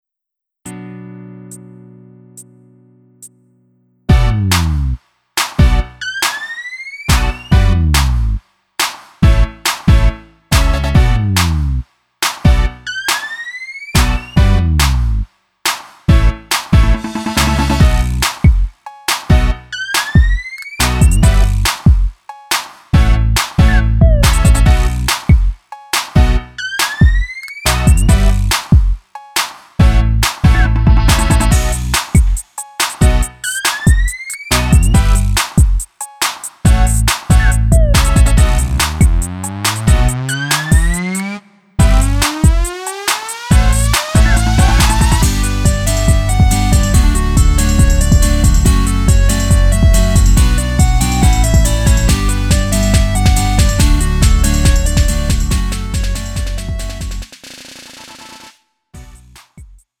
음정 원키 2:53
장르 구분 Lite MR